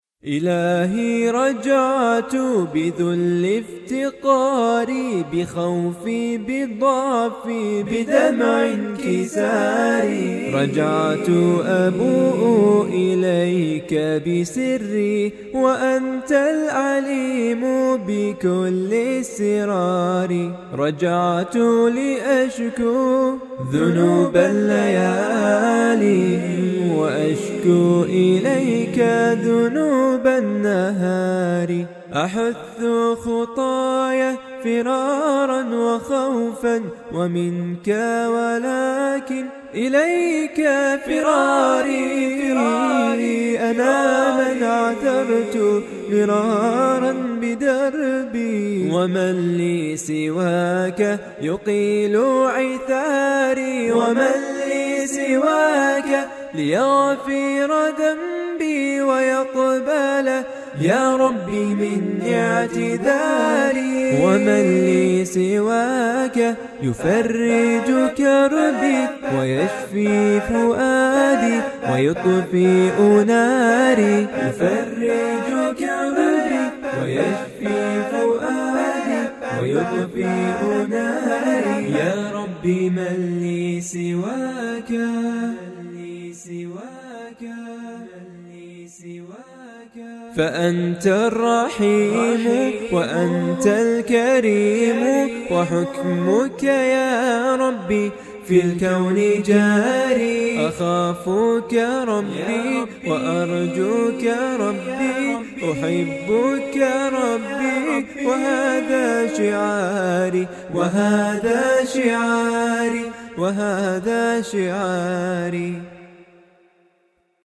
انشاد وكورال والحان وهندسة وتوزيع
أعجبني اللحن .. روحاني !
بس لو فيها ديلي بعيد شوي .. كان تطلع أحلى
الله يجزاك الجنة و يخي لنا هالصوت الحلو ..